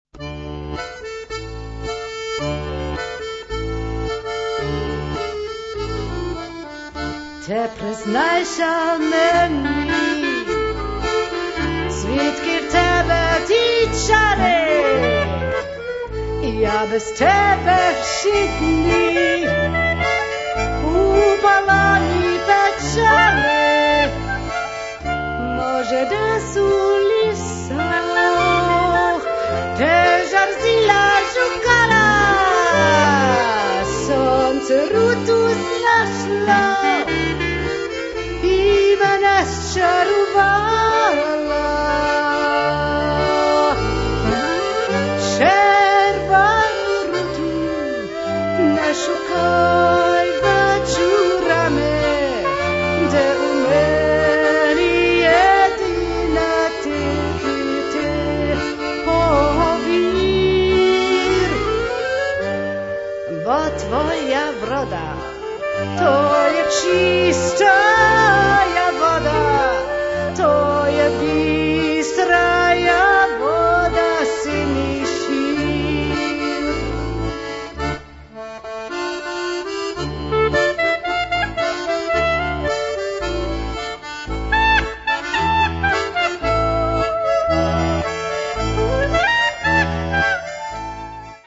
Aufgenommen in Park Studios, Linz/Austria